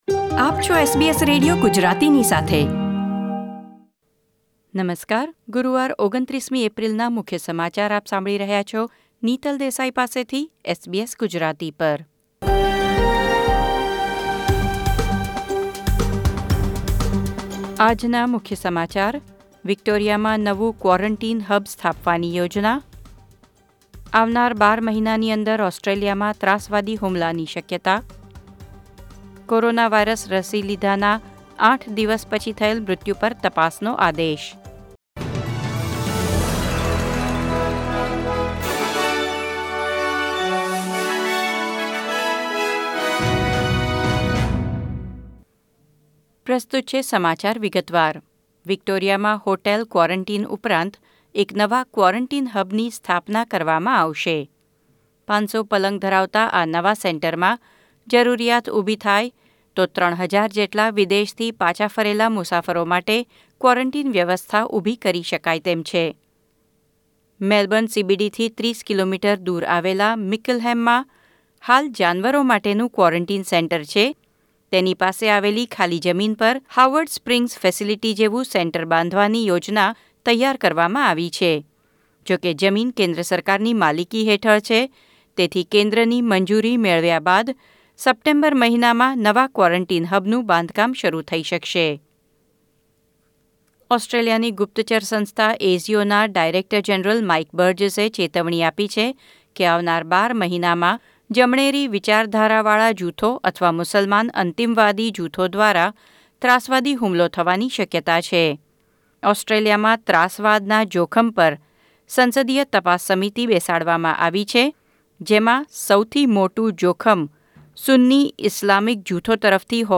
SBS Gujarati News Bulletin 29 April 2021